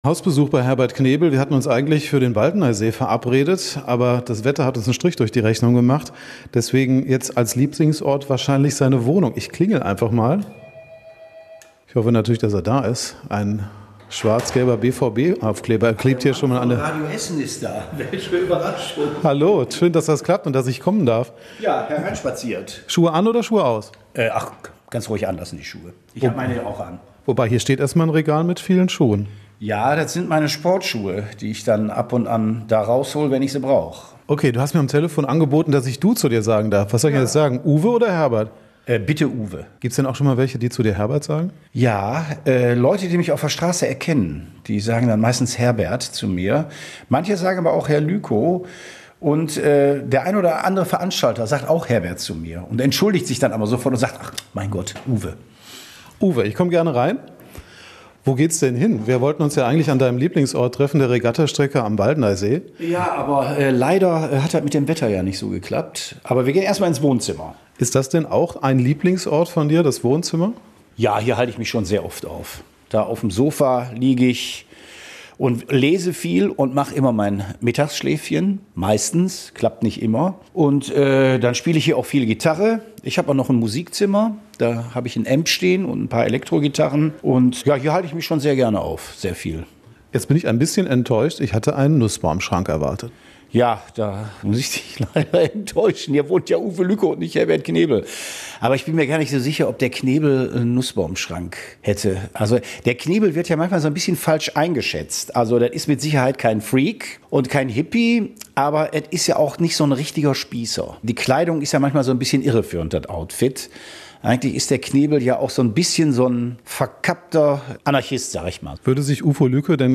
Vor seinem Auftritt im Grugapark im August treffen wir den Essener Comedian Herbert Knebel alias Uwe Lyko in seiner Wohnung in Werden und sprechen ganz privat.